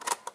play dn.aiff